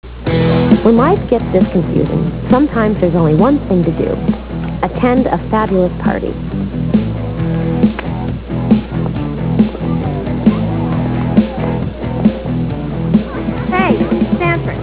Comment: rock